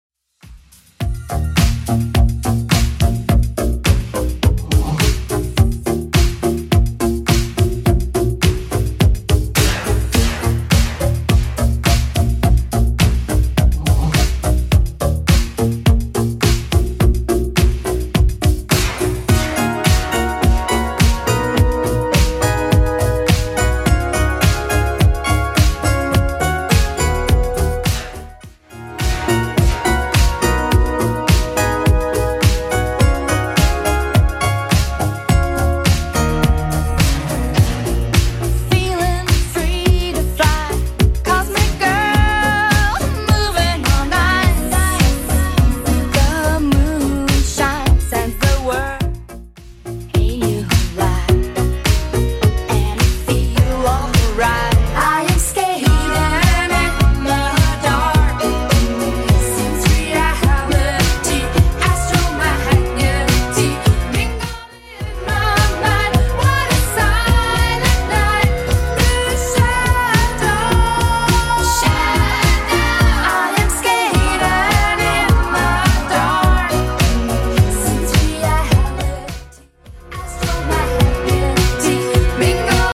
Genre: 70's